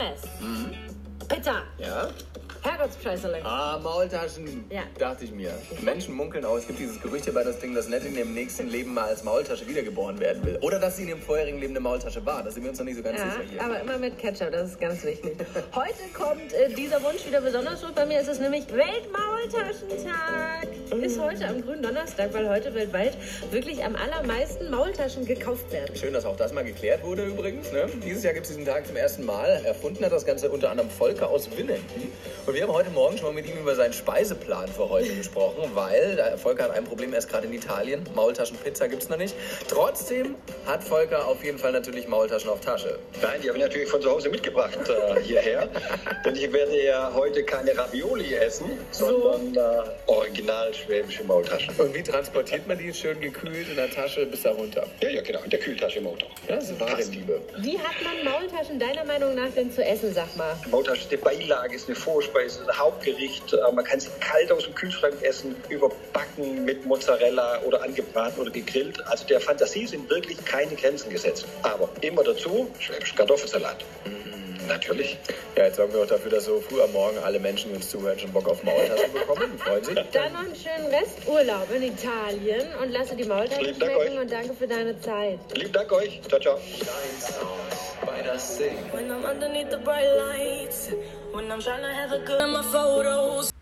Radiosender aus Baden-Württemberg haben sich am Gründonnerstag bei mir gemeldet. Dabei sind ein paar Interviews rausgekommen, wie das hier früh morgens mit DAS DING 🙂